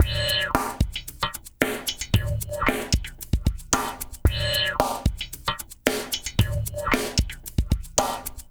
LQT FUNK M-R.wav